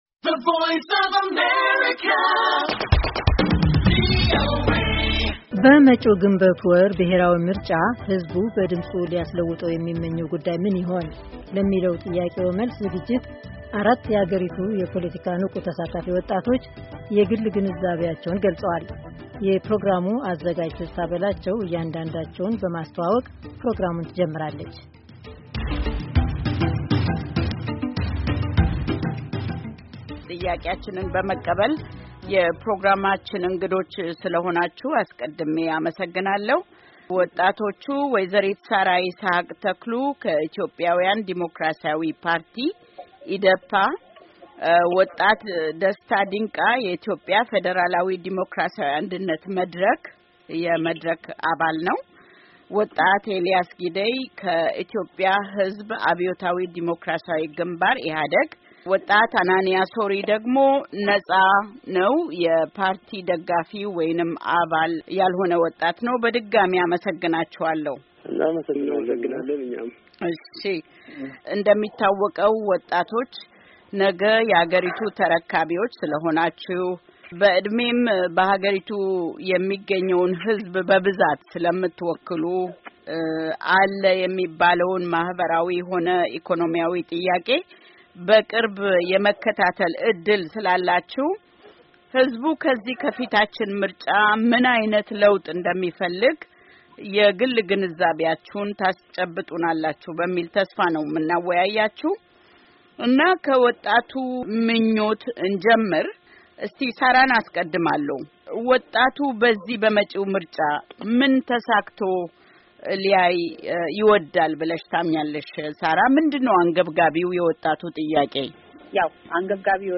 What Ethiopian Youth wish to change by ballots? Discussion on Call-in Shaw VOA 04-18-15